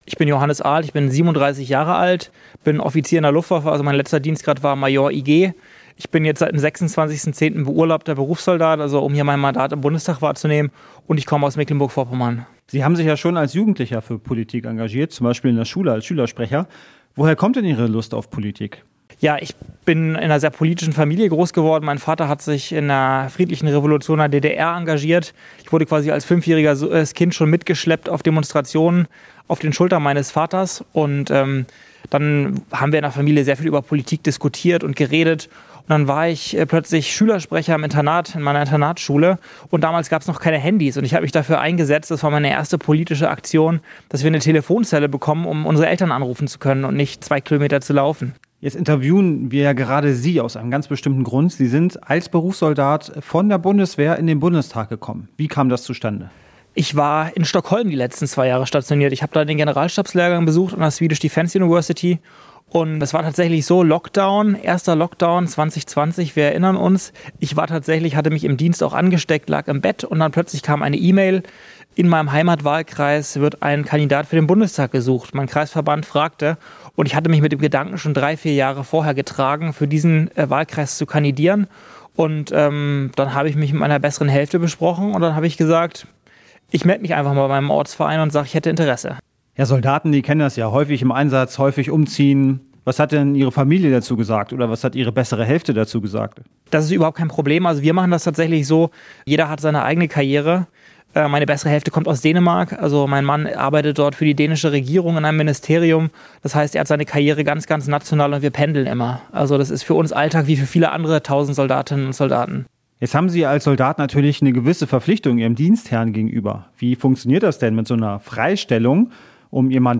Interview mit Johannes Arlt